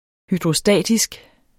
Udtale [ hydʁoˈsdæˀdisg ]